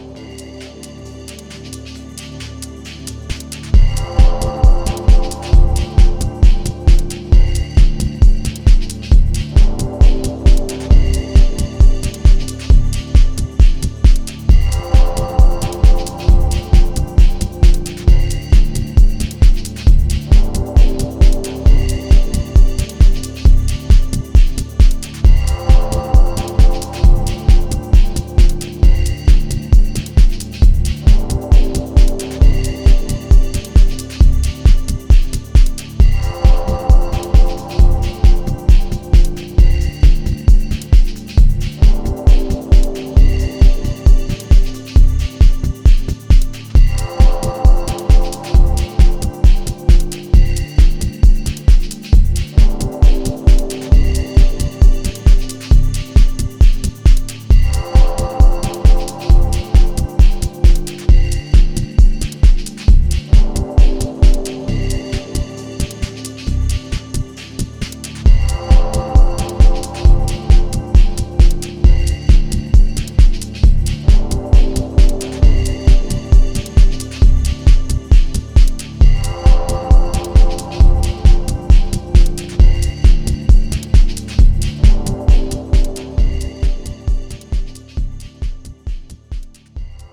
彼の地伝統のダブテックマナーなテクスチャーで綴られたディープ・ハウスチューン